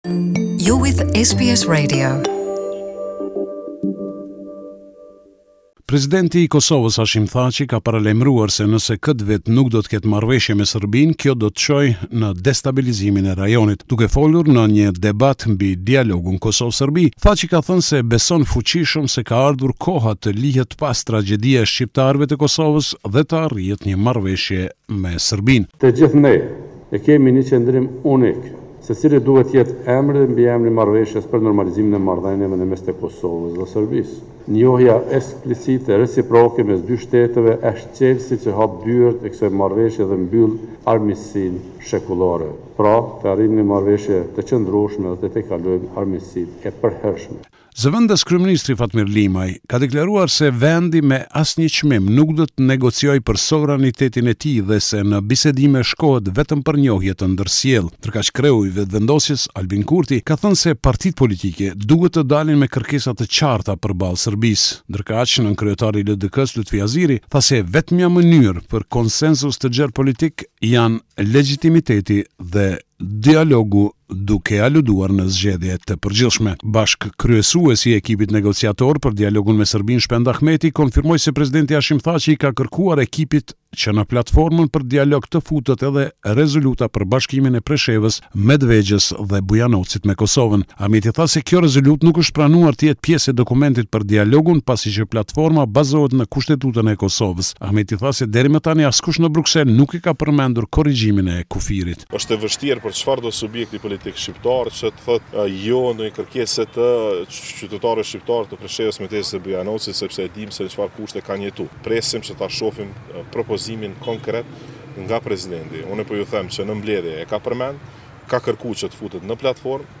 This is a report summarising the latest developments in news and current affairs in Kosovo